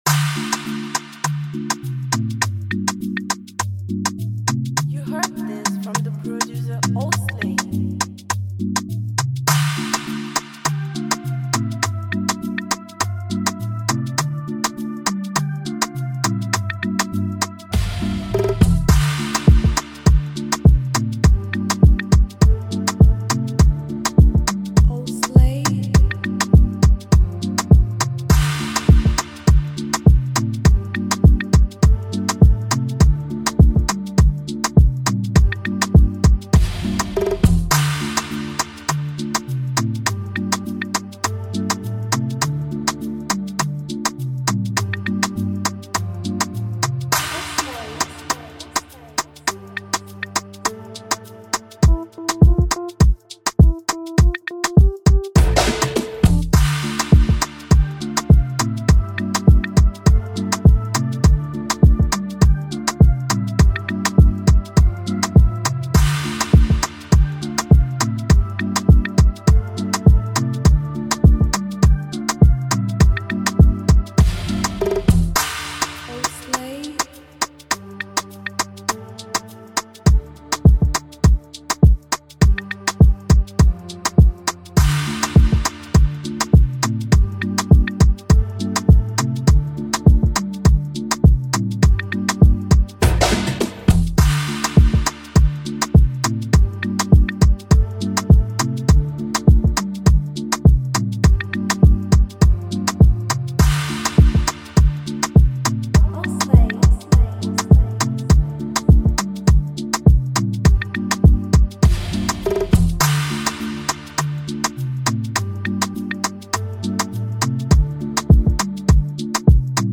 delivers a smooth, soulful Afrobeat instrumental